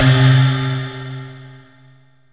SYNTH GENERAL-1 0010.wav